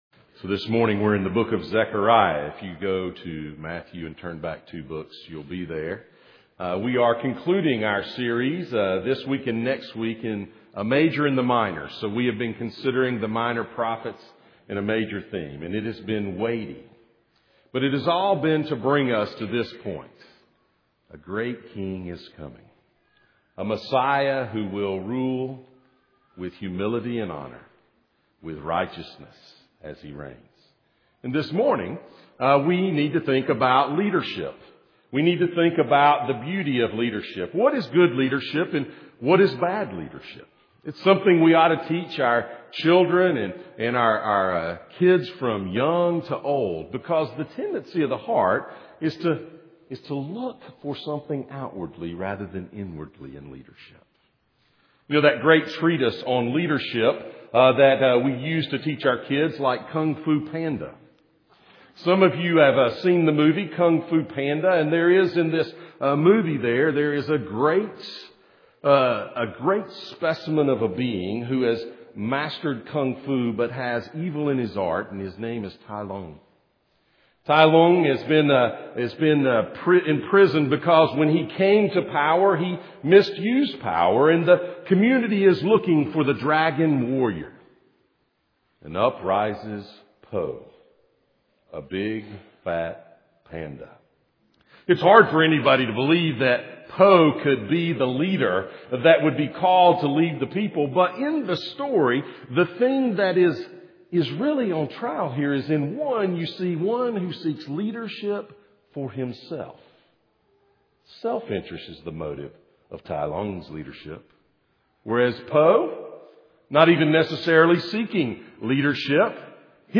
Passage: Zechariah 9:9-10:4 Service Type: Sunday Morning